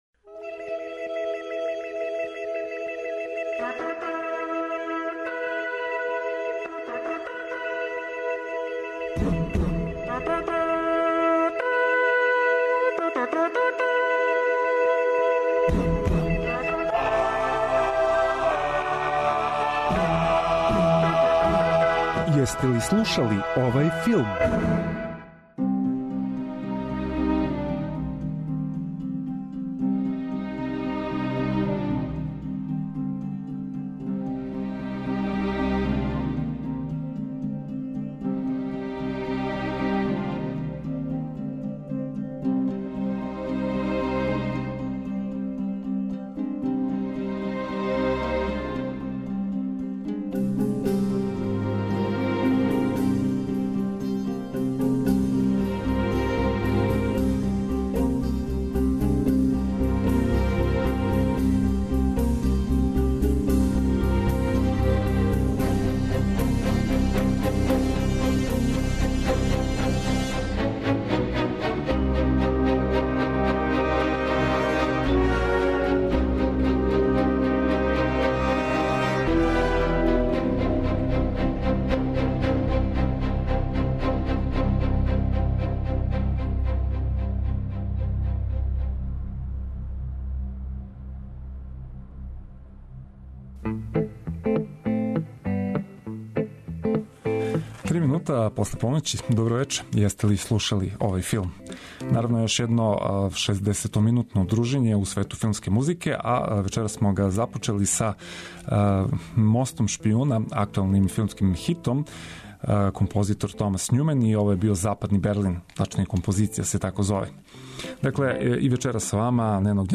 Autor: Београд 202 Филмска музика и филмске вести.